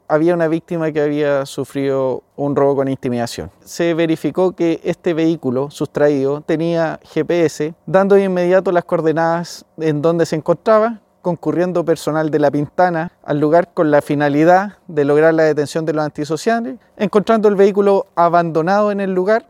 Así lo relató el capitán